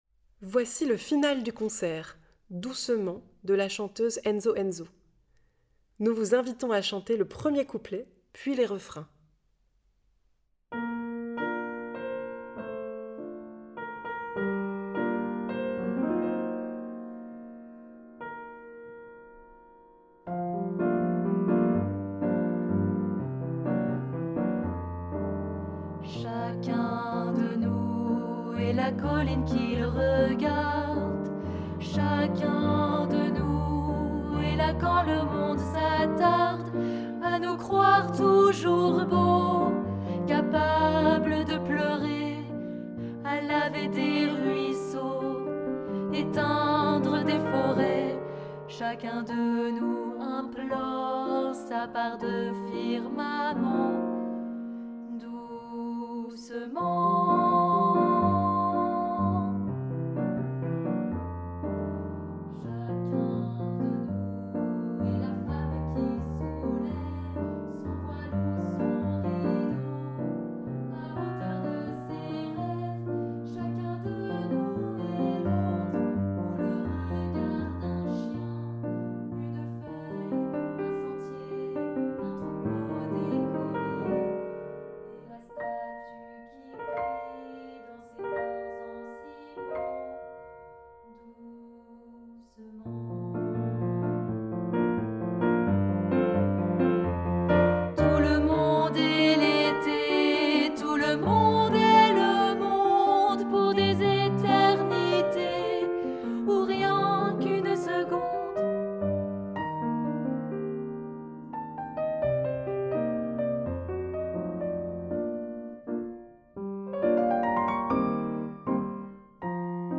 Genre :  Chanson
Version tutti choeur participatif